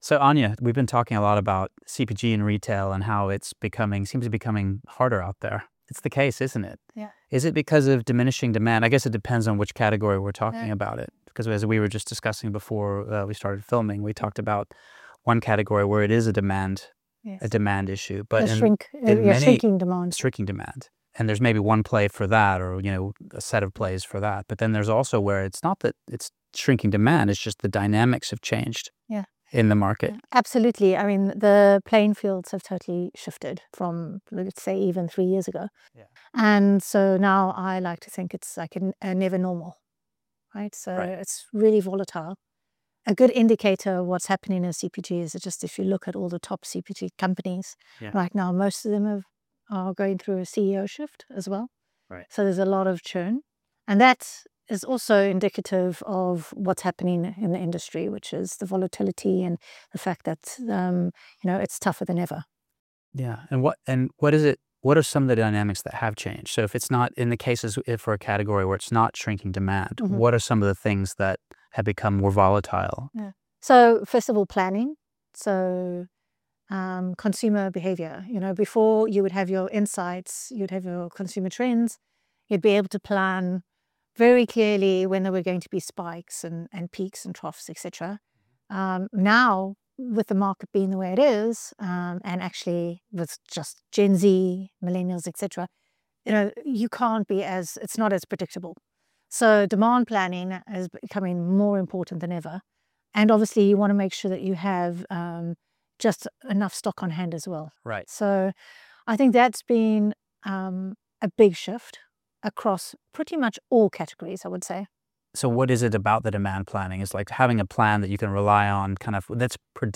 Find out more CPG and retail in an ever-changing market In conversation